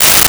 Paper Tear 04
Paper Tear 04.wav